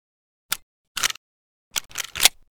aps_reload.ogg